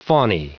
Prononciation du mot faunae en anglais (fichier audio)
Prononciation du mot : faunae